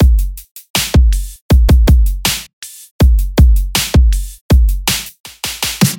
Percussion Electro Drip Drum Loop
标签： 160 bpm Electro Loops Drum Loops 1.01 MB wav Key : Unknown
声道立体声